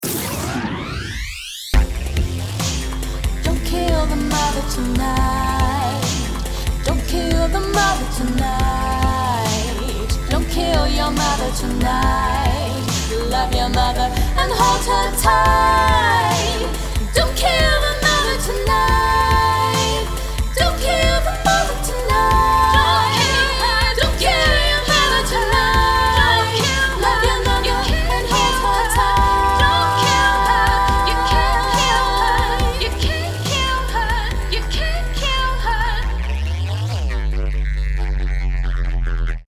Jingles Sometimes I must hammer on the piano to sort out my feelings. Here is one semi-serious song, plus a few strange ditties I wrote for a web series called “Cooking With Carter,” which is an existential cooking show. 3:34 Sad Man Artist (Quel Dommage) 0:30 Corned Beef Hash 1:27 See You in The Soup 0:13 Big Baking 0:39 Don't Kill The Mother